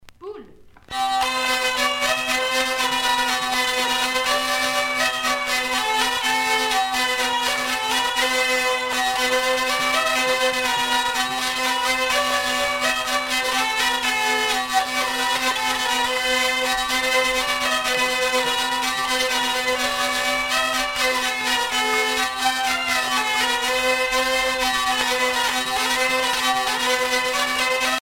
danse : quadrille : poule
Pièce musicale éditée